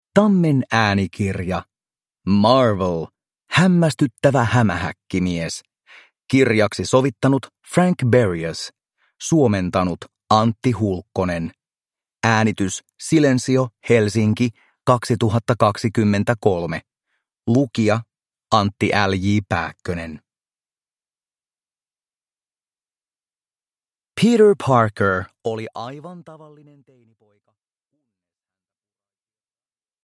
Uppläsare: Antti L. J. Pääkkönen